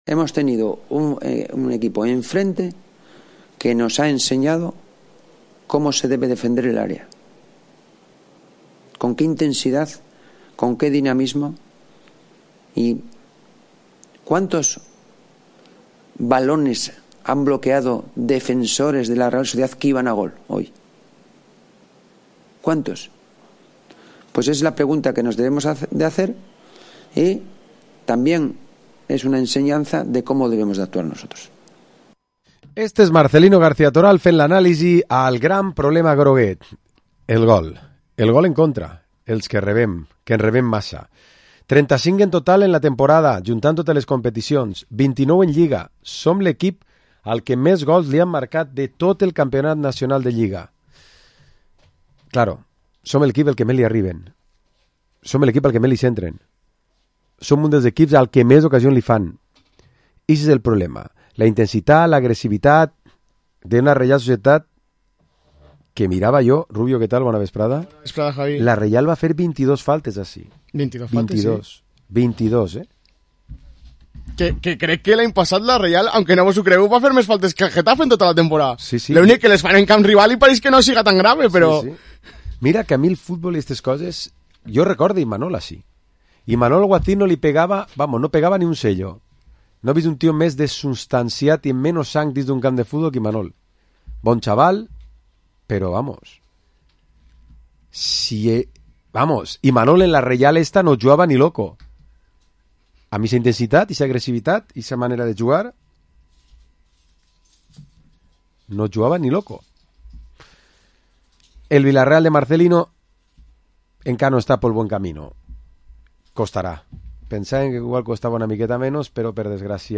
Programa esports tertúlia dilluns 11 de Desembre